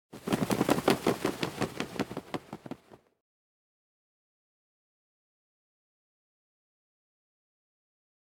flutter.ogg